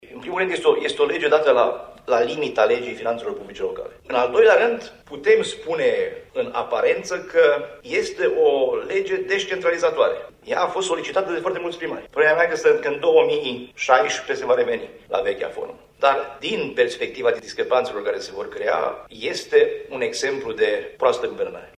Subiectul a fost abordat astăzi, în cadrul unei conferinţe de presă susţinută la Reşiţa, de către Preşedintele Consiliului Judeţean Caraş-Severin, Sorin Frunzăverde.